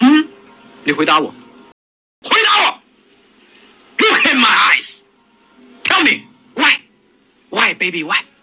IA de voix off masculine humoristique et spirituelle
Générez une narration hilarante avec une voix IA masculine charismatique et drôle conçue pour les sketchs comiques, les mèmes et la narration engageante.
Synthèse vocale